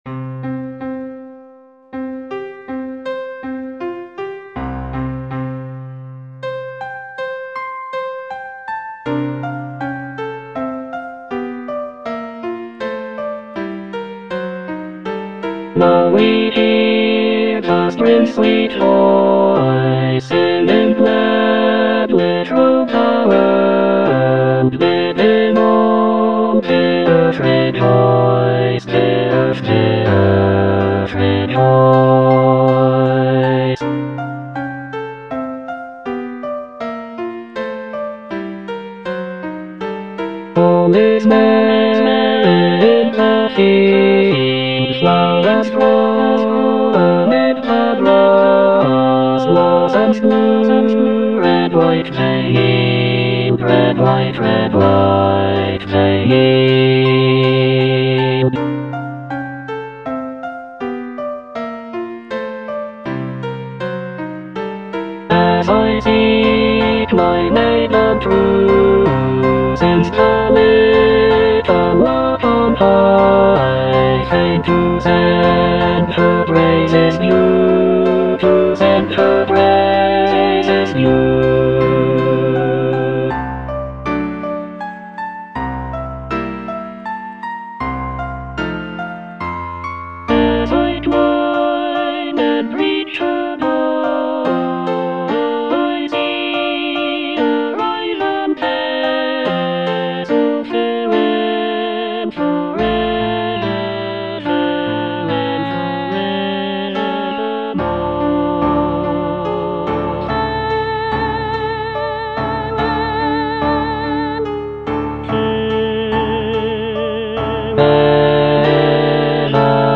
(bass II) (Emphasised voice and other voices) Ads stop